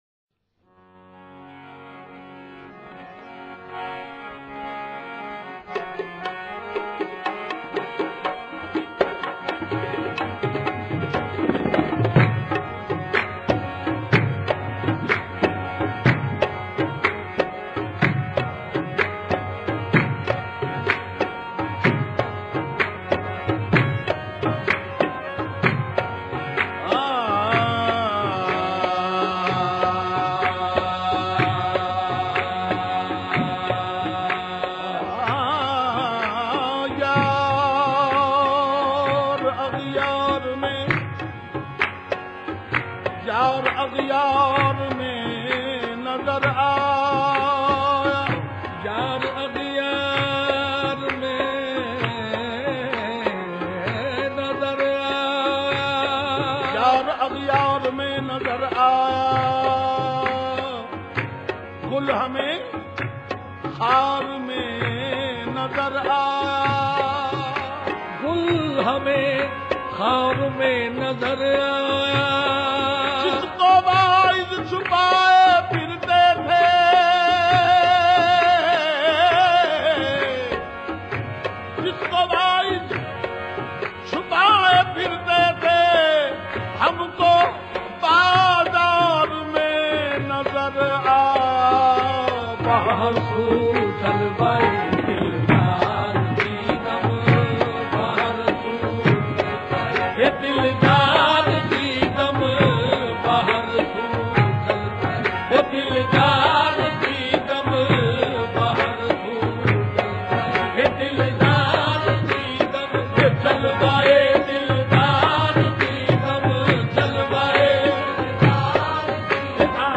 Farsi Qawwali